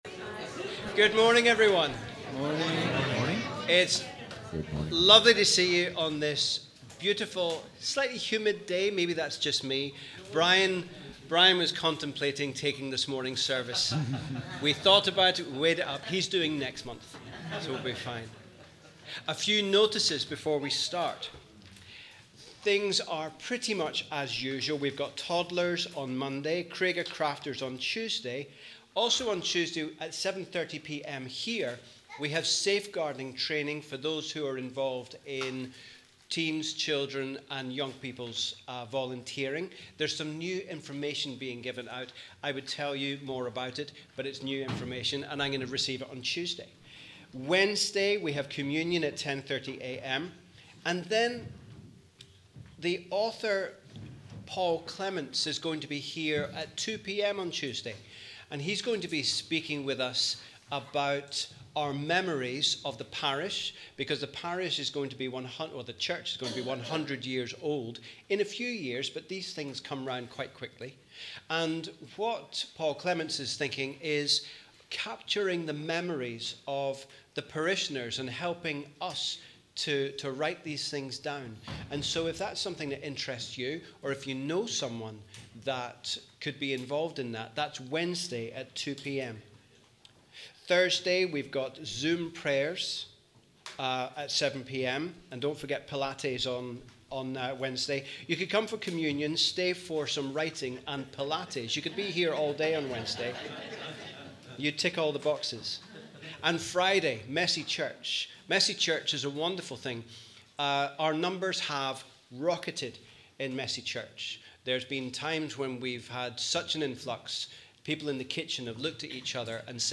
Wherever you are, we welcome you to our Connect service on the first Sunday after Trinity, as we continue to think about the Kingdom of God.